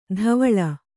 ♪ dhavaḷa